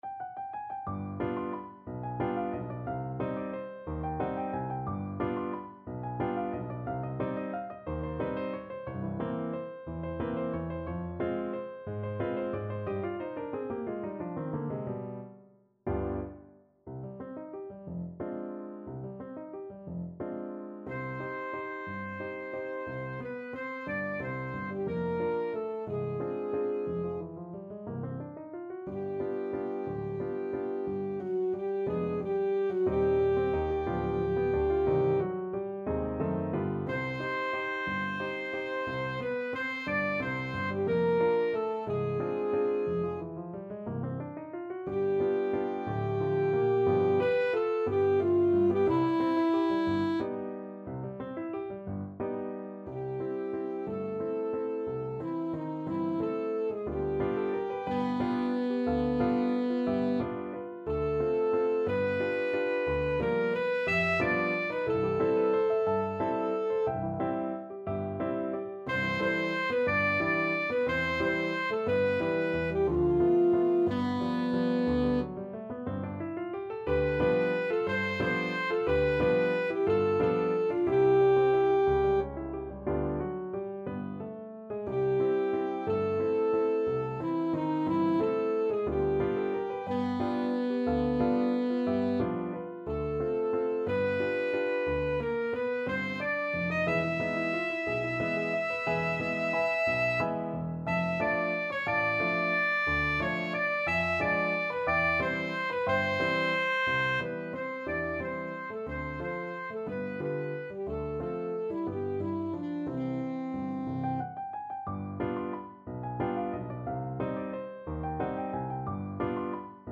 Alto Saxophone
Allegro movido =180 (View more music marked Allegro)
3/4 (View more 3/4 Music)
Classical (View more Classical Saxophone Music)
Mexican